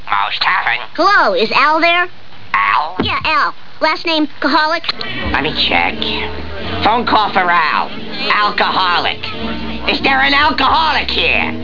Bart's Prank calls
Bart calling Moe for "Al Coholic"